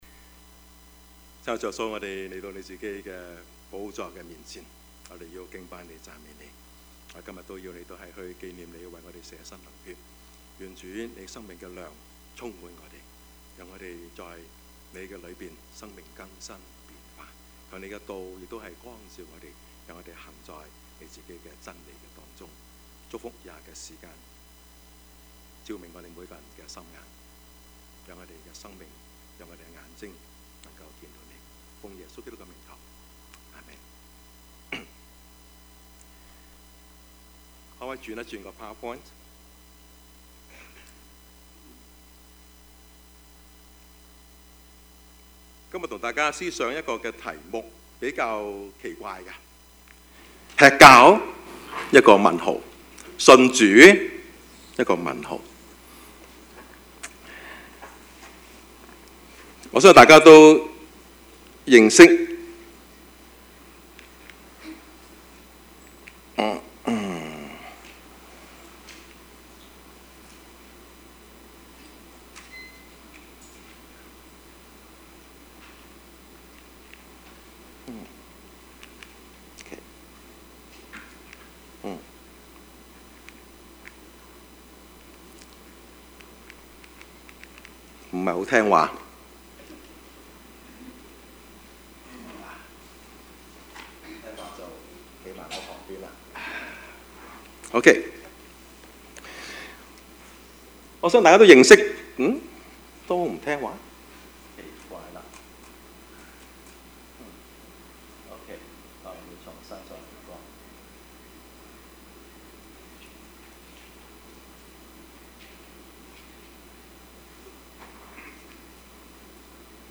Service Type: 主日崇拜
Topics: 主日證道 « Clean Bandit 偏見與固執 »